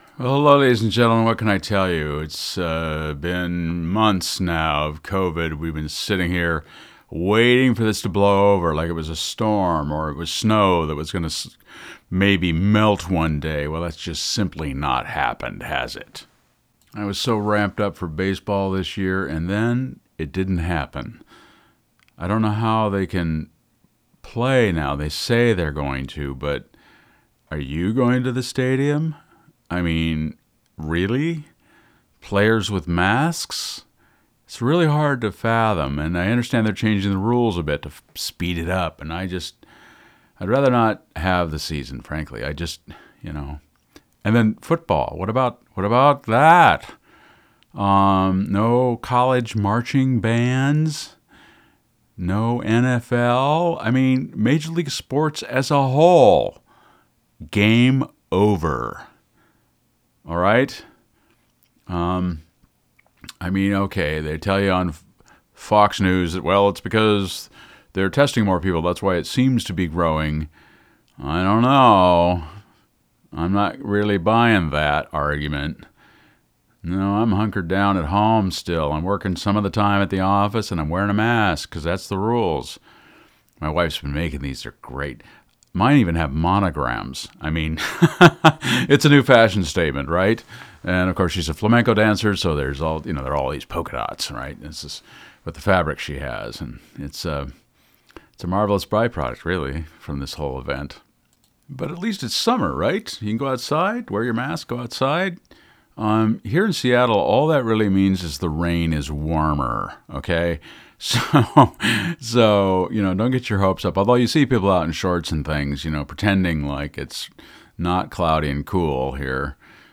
Here I had just a few minutes to sit in the studio and say what has been on my mind, then recognized we have what amounts to a royal battle on our hands. I reached for my copy of Shakespeare’s complete works, and recite from habit the courageous speech of Henry V, because I need the inspiration to solider on.